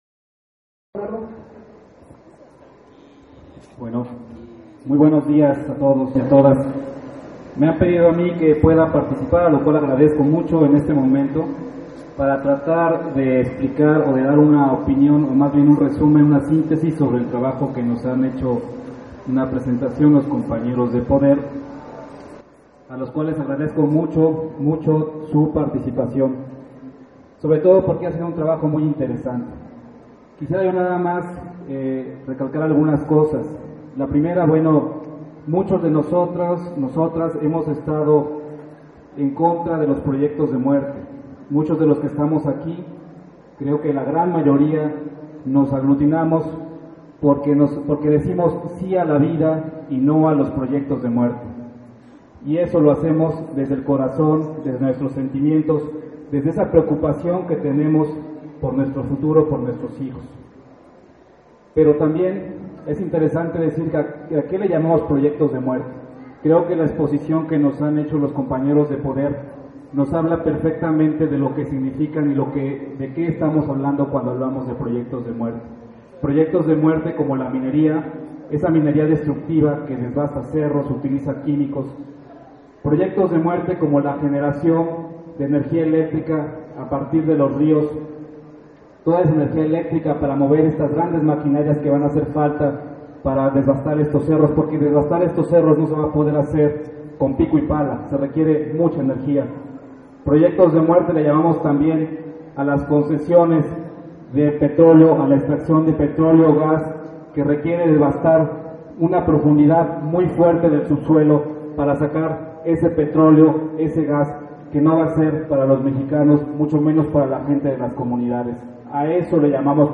Este 22 de junio se llevó a cabo el foro Proyectos de muerte en la sierra norte de Puebla y su impacto en los Derechos Humanos, en la cancha municipal de Ixtacamaxtitlán en la Sierra Norte de Puebla (SNP), al cual acudieron más de mil personas, habitantes y autoridades de más de 20 comunidades de esa zona.